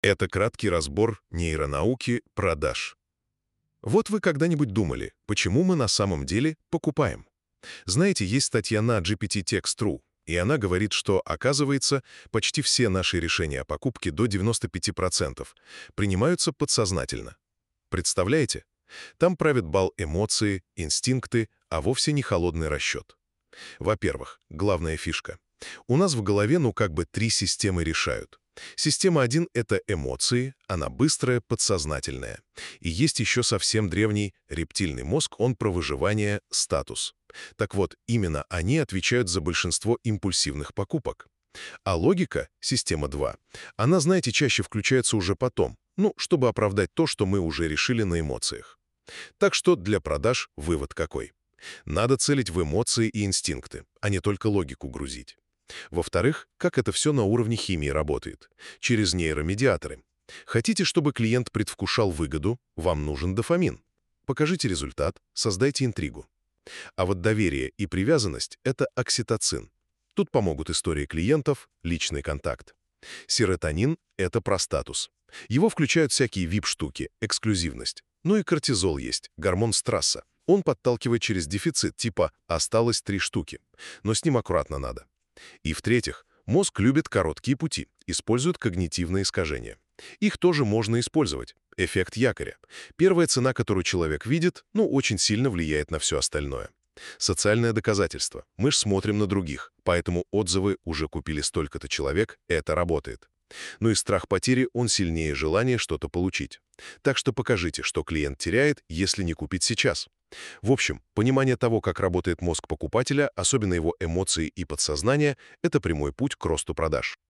neuronauka-prodazh-audio-pereskaz-gpttext.mp3